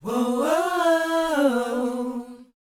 WHOA B A U.wav